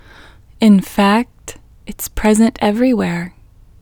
WHOLENESS English Female 18